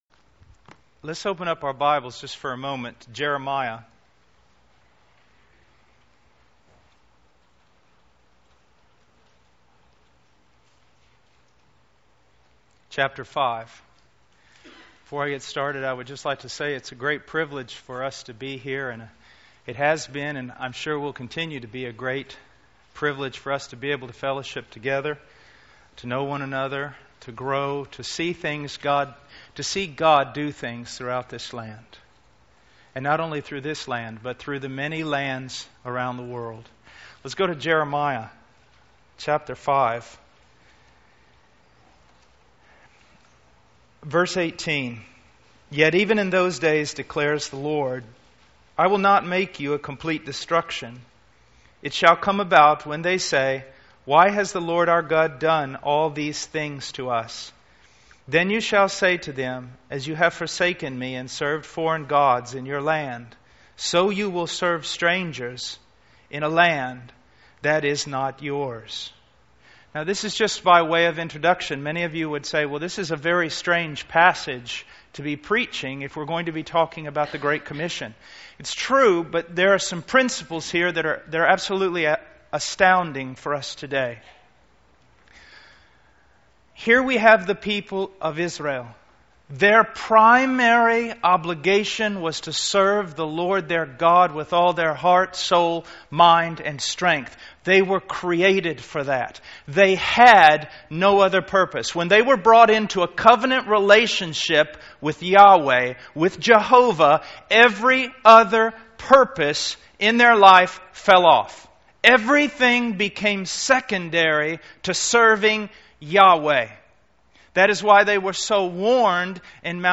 In this sermon, the preacher emphasizes the importance of not getting distracted from the main purpose of Christianity. He highlights that Christianity is not just about being moral or making right decisions for personal blessings, but it is primarily about loving God with all our heart, soul, mind, and strength, and then loving our neighbor as ourselves.